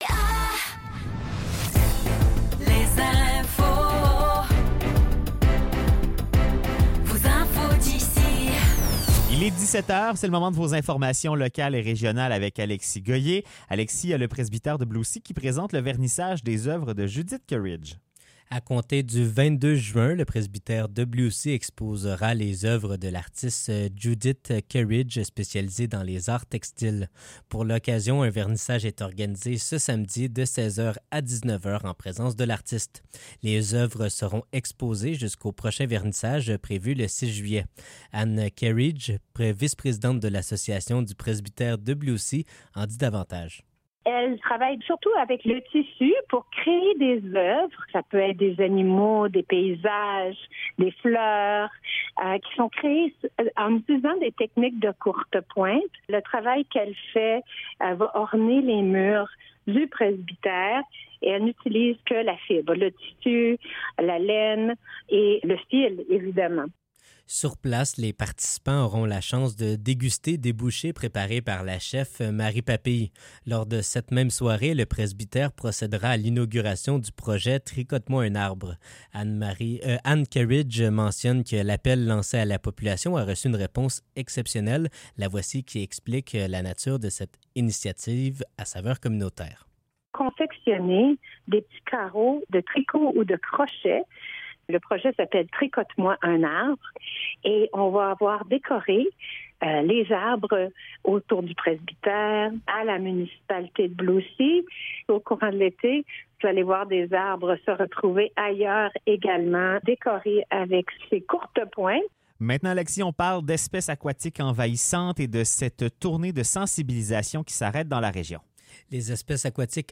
Nouvelles locales - 19 juin 2024 - 17 h